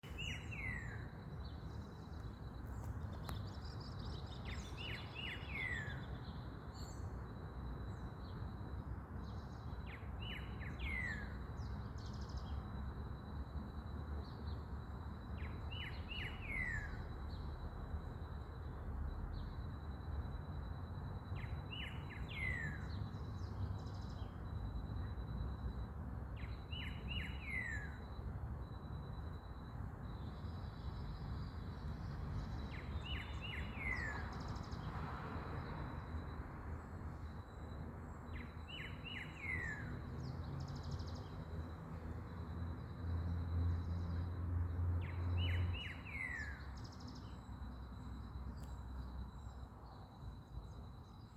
Bluish-grey Saltator (Saltator coerulescens)
Location or protected area: San Miguel de Tucumán
Condition: Wild
Certainty: Recorded vocal